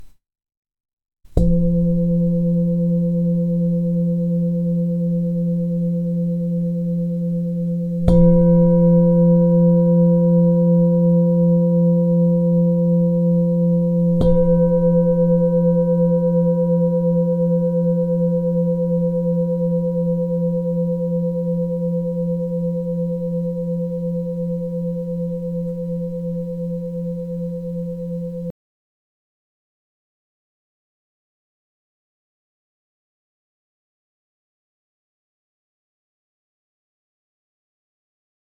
Lunární tibetská mísa E3F3 26cm
Je výjimečná kombinací silných stěn a vysokých tónů.
Nahrávka mísy úderovou paličkou:
Její zvuk je zcela jedinečný.